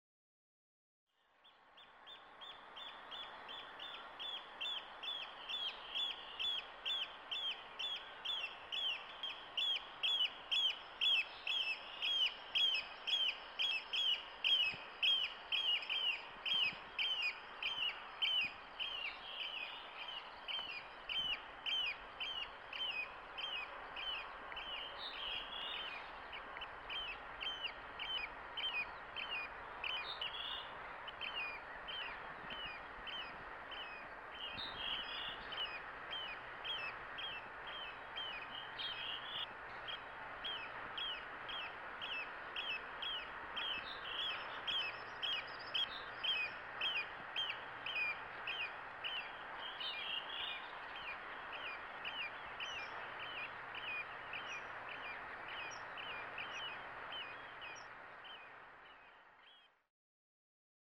Meluisat meriharakat